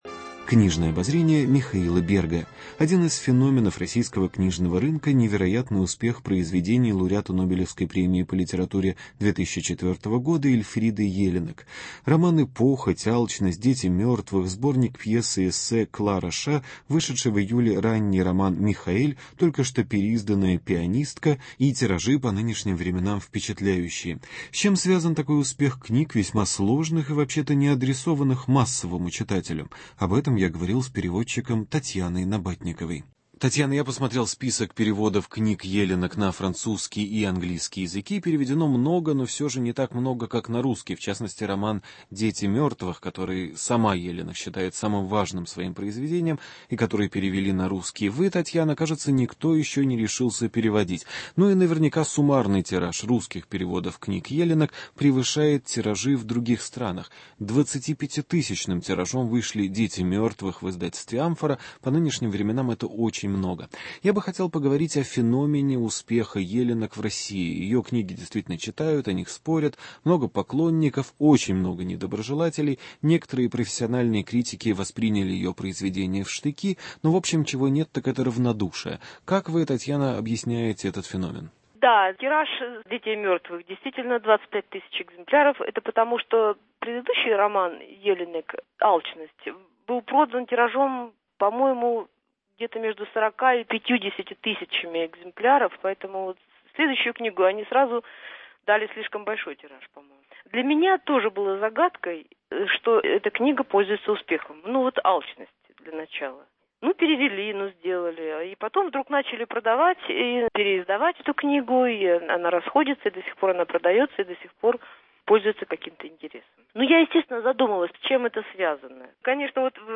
Феномен успеха Эльфриды Елинек в России: интервью